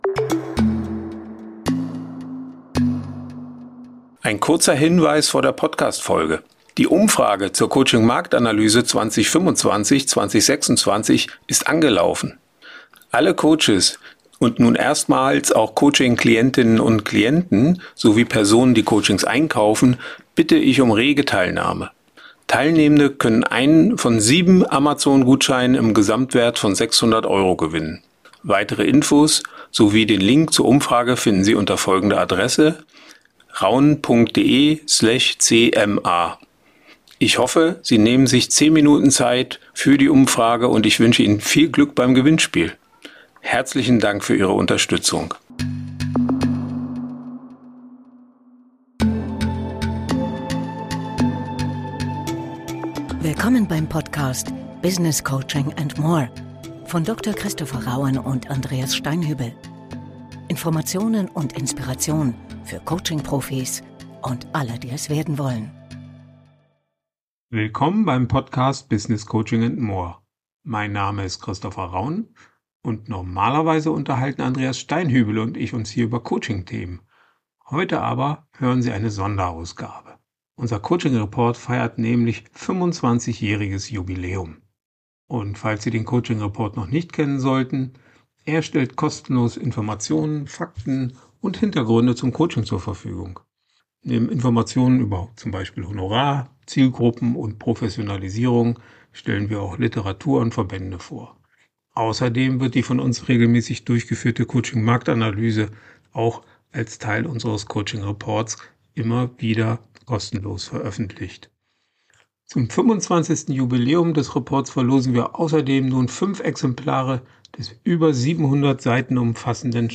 künstlicher Intelligenz erstellt, die Inhalte stammen aber von uns